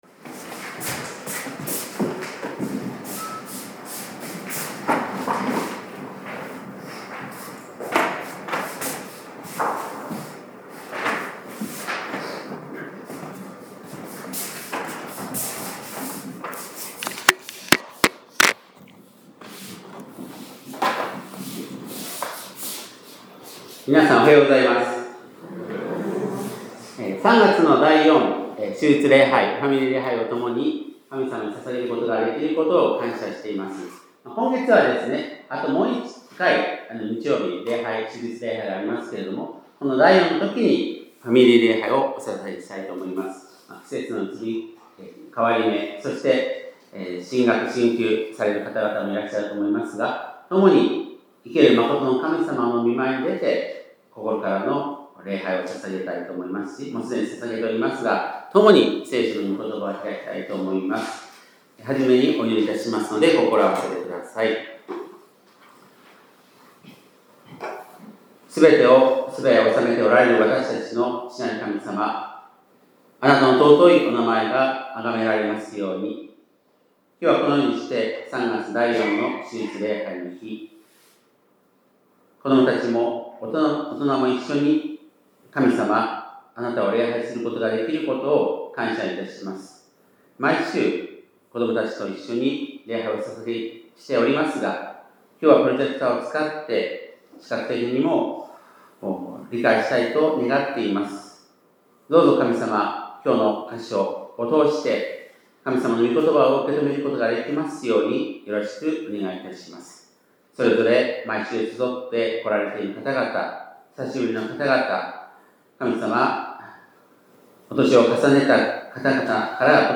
2026年3月22日（日）礼拝メッセージ